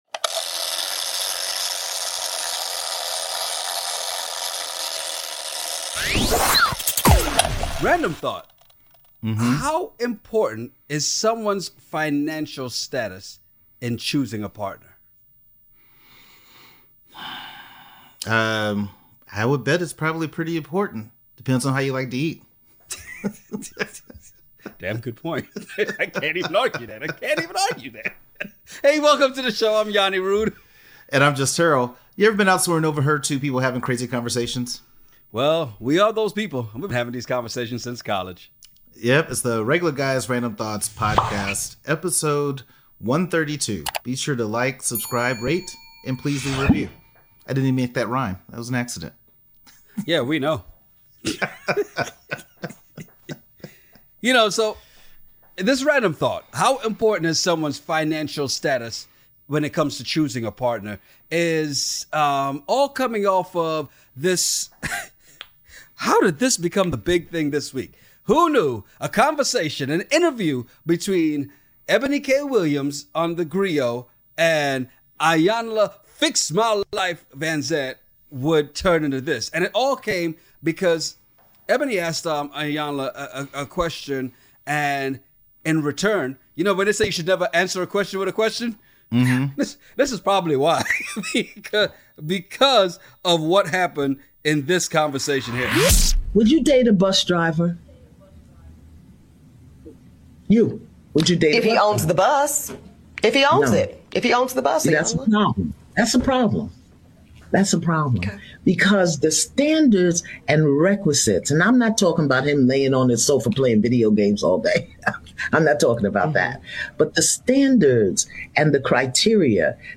Well we are those guys and we have been having these conversations since college.